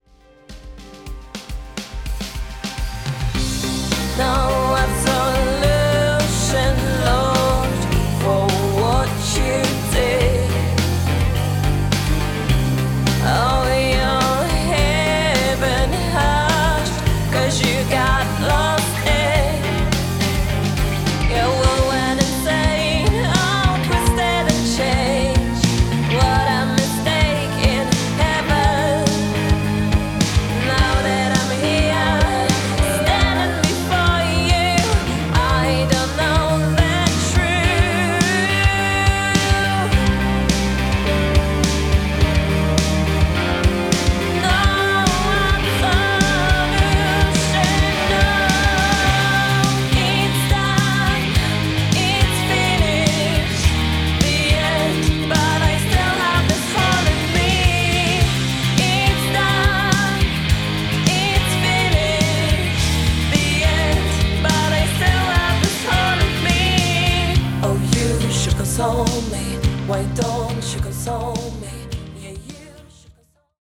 GOTHIC POPROCK